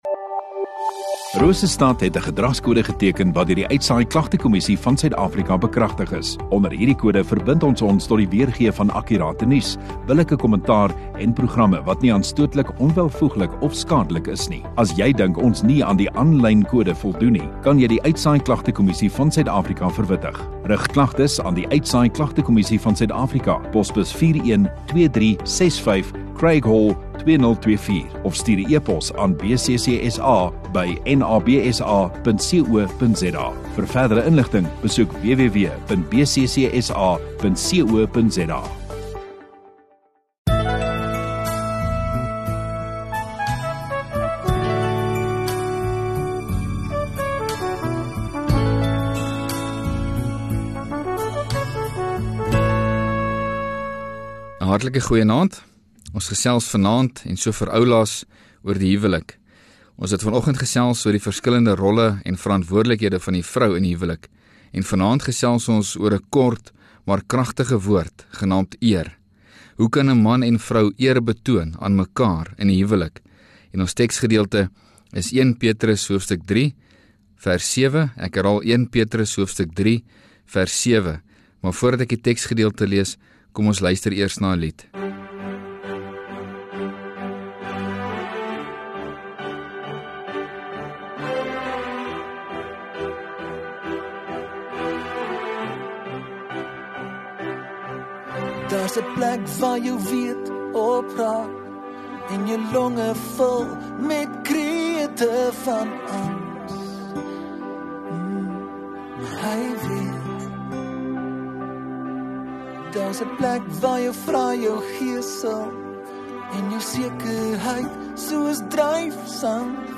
17 Nov Sondagaand Erediens